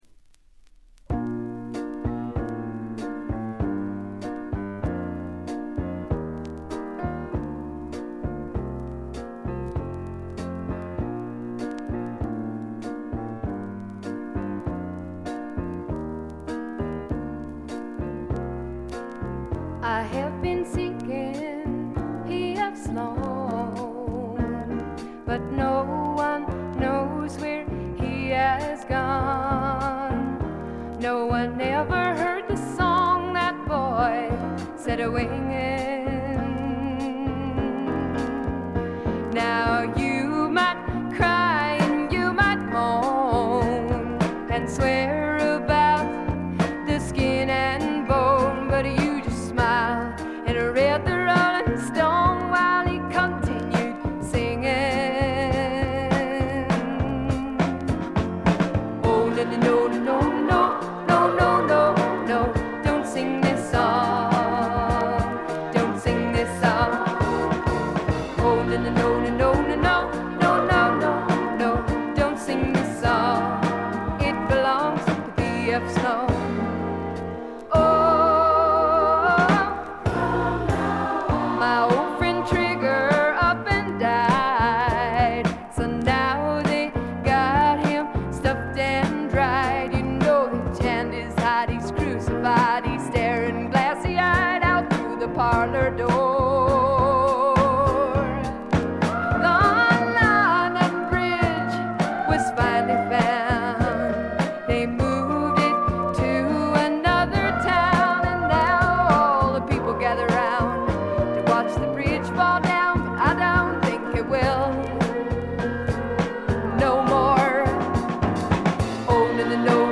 細かなチリプチ程度でまずまず良好に鑑賞できると思います。
試聴曲は現品からの取り込み音源です。
vocals